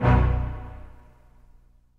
brass vox hit 1.wav